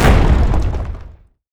earthimpact.wav